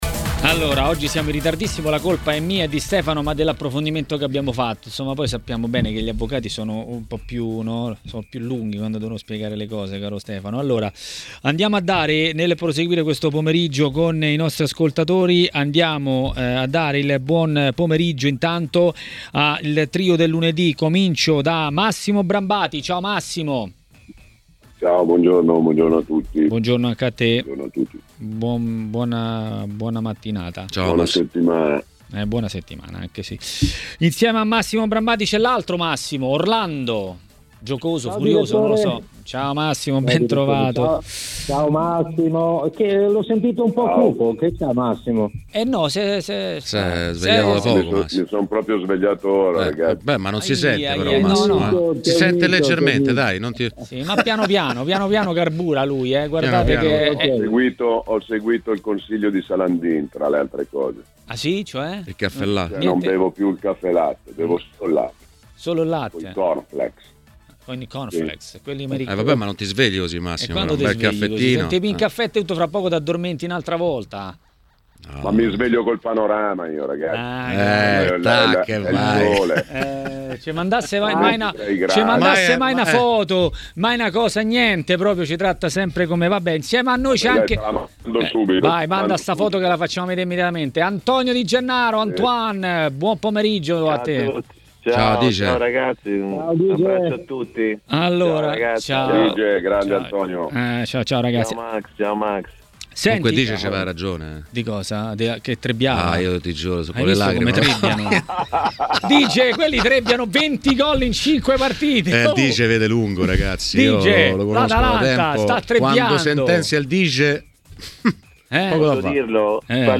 A TMW Radio, durante Maracanà, l'ex calciatore e commentatore tv Antonio Di Gennaro ha parlato della giornata di campionato.